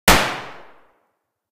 m4a1_shot.ogg